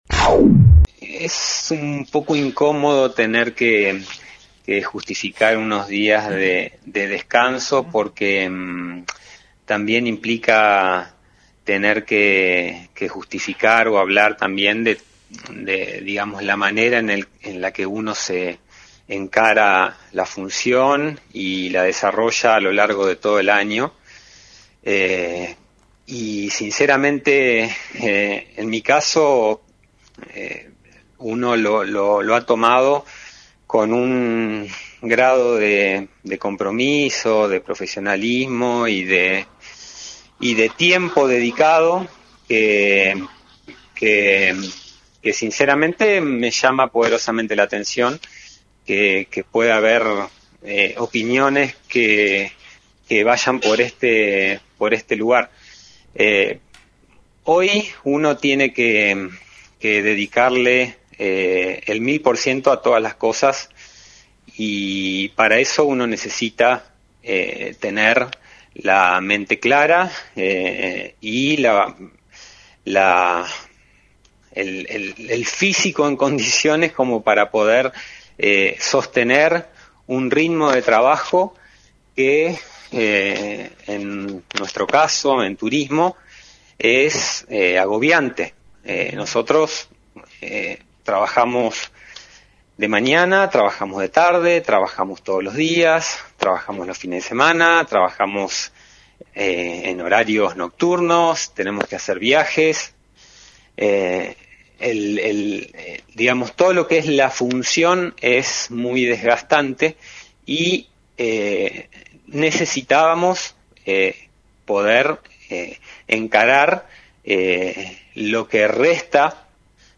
El director de Turismo, Martín Lima, realizó un fuerte descargo en FM 90.3 respecto a los cuestionamientos sobre sus vacaciones a fines del mes de enero. Además contó detalles de las internas con las que se enfrenta dentro del municipio lo que lo llevó a postular su renuncia si no se realizaba el cambio de Secretaría bajo la cual orbitaba la dirección de la que está a cargo.